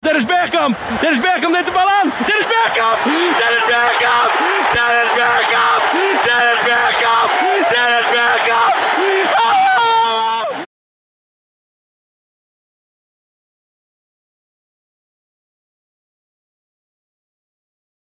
What name did commentator Jack van Gelder shout repeatedly in the last minutes of the match between Argentina and The Netherlands, in the 1998 World Cup?
Commentator Jack van Gelder conveyed the emotion of the Dutch fans after striker Dennis Bergkamp scored against Argentina in the 1998 World Cup quarterfinals.